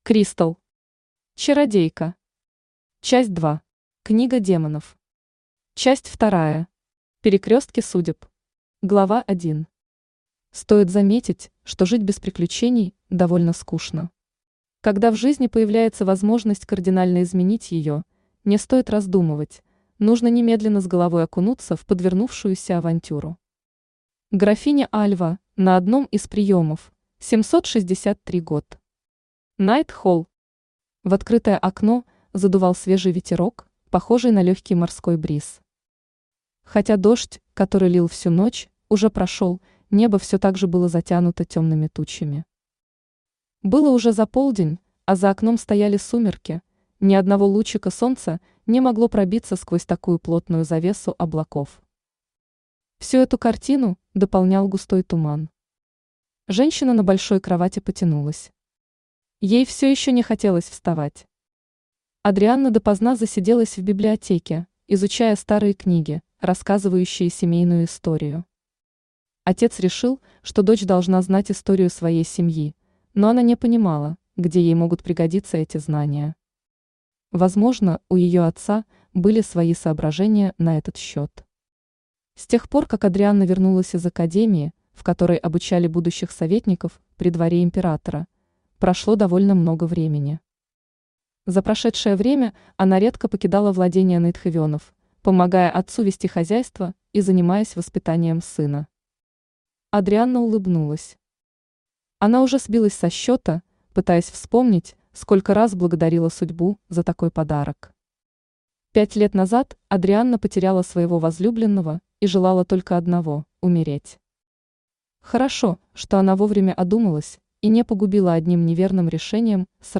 Аудиокнига Чародейка. Часть 2. Книга демонов | Библиотека аудиокниг
Книга демонов Автор Crystal Читает аудиокнигу Авточтец ЛитРес.